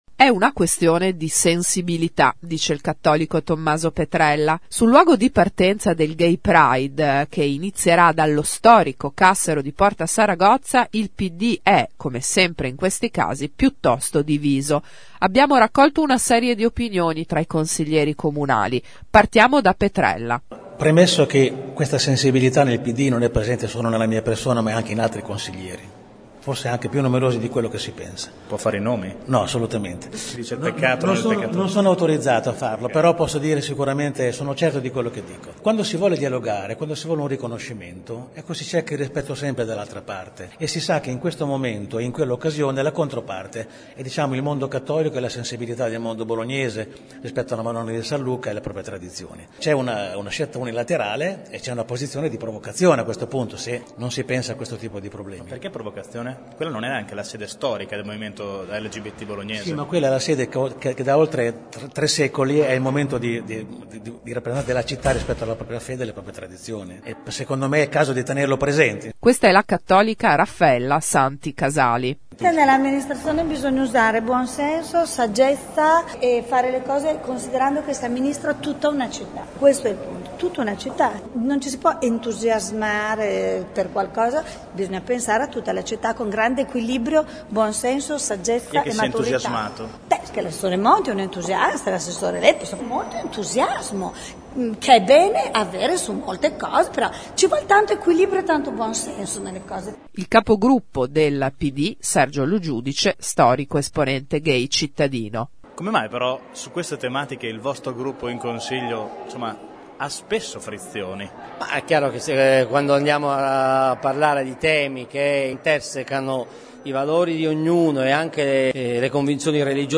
voci Pd